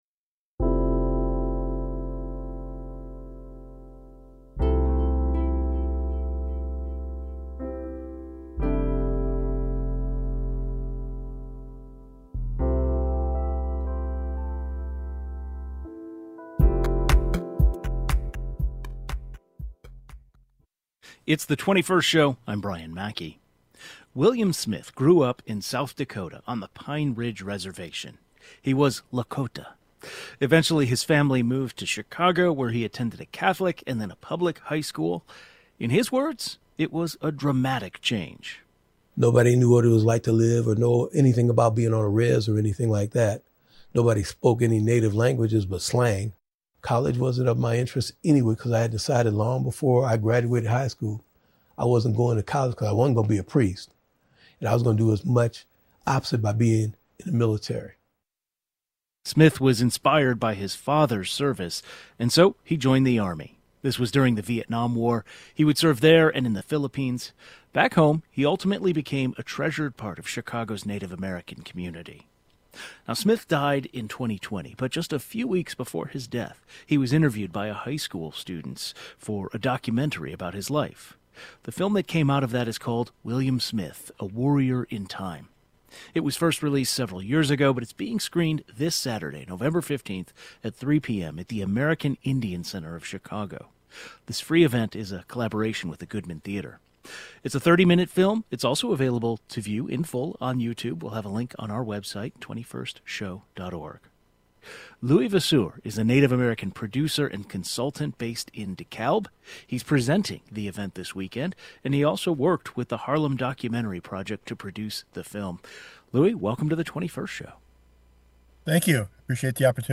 Some high school students in Illinois co-produced a film on his life, which is to be screened at Chicago's Goodman Theater this weekend. The 21st Show is Illinois' statewide weekday public radio talk show, connecting Illinois and bringing you the news, culture, and stories that matter to the 21st state.